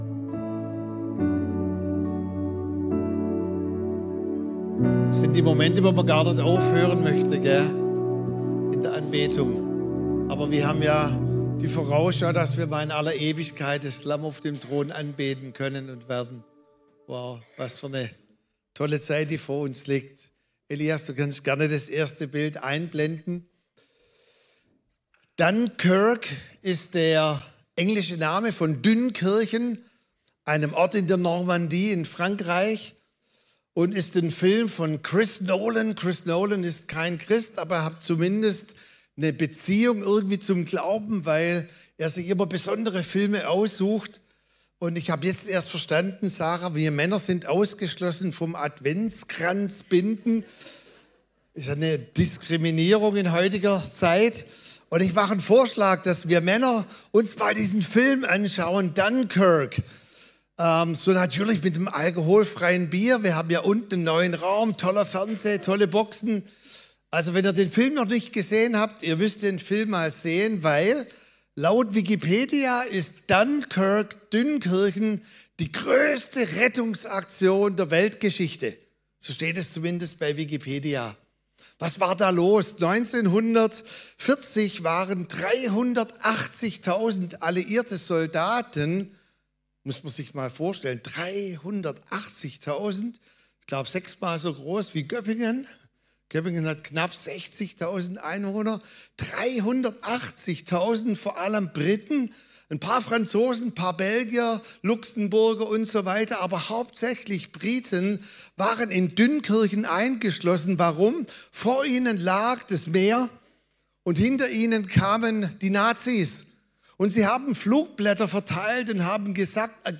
Sonntagspredigten